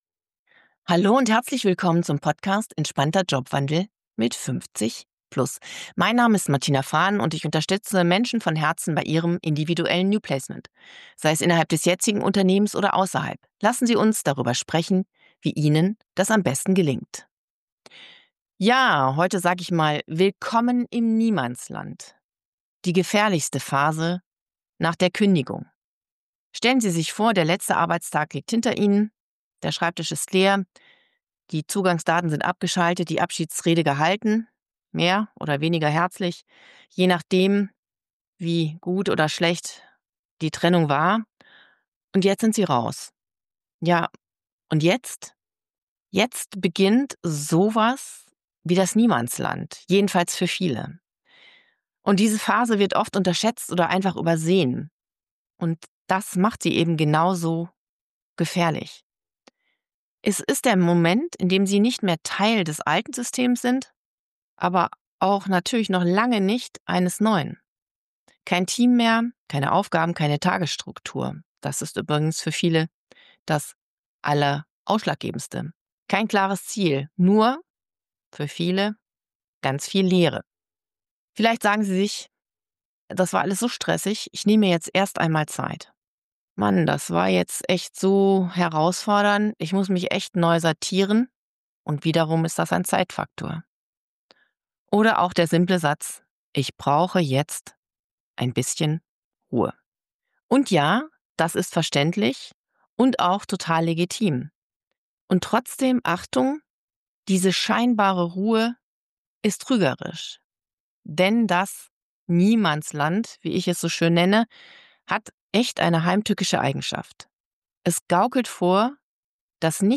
verlieren, erfahren Sie in dieser neuen Solo-Episode.